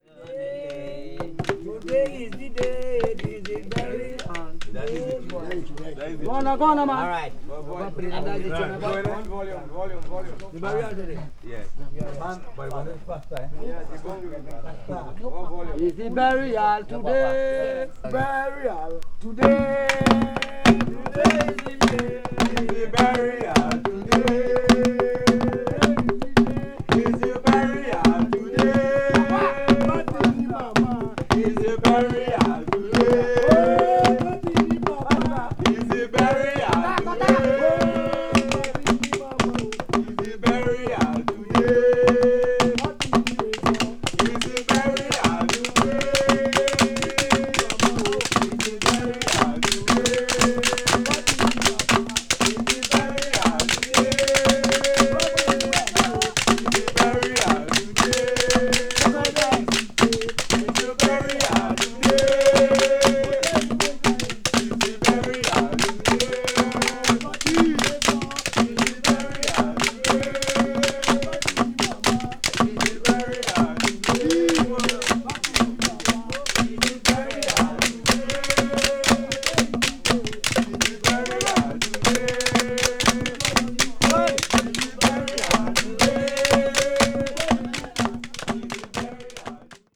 media : VG+/VG+(light surface noises and click noises caused by slightly wear and hairlines.)
It features field recordings made in the Caribbean island nation of Trinidad.
This is a valuable document showcasing traditional percussive music from the region.
afro-caribbean   ethnic music   field recording   primitive   trinidad   world music